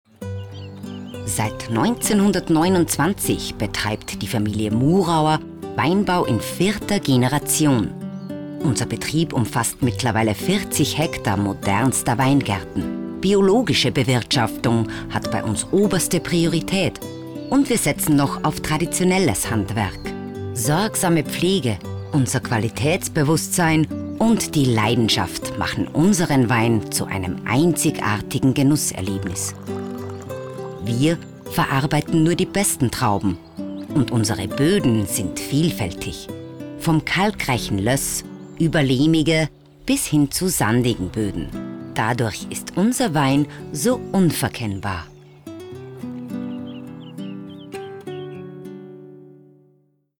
Imagefilm